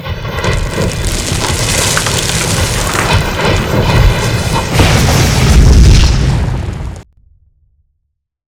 cave.wav